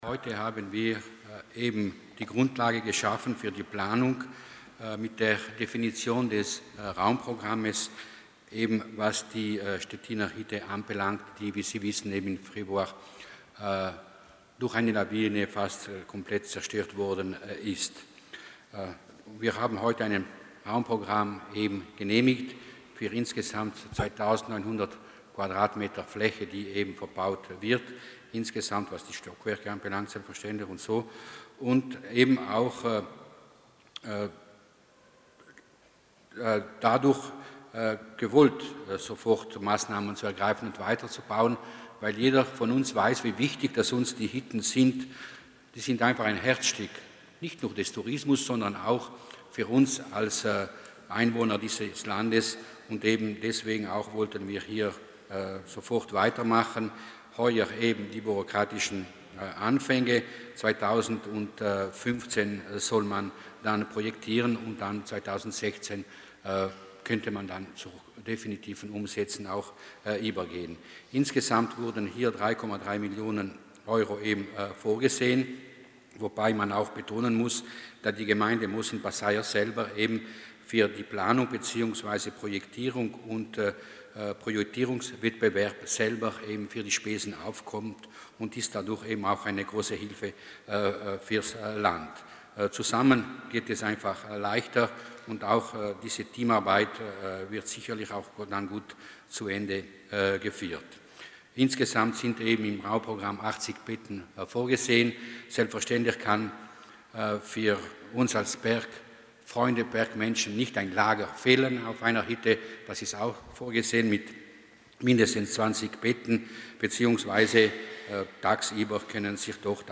Landesrat Mussner erläutert das Projekt für die Stettinerhütte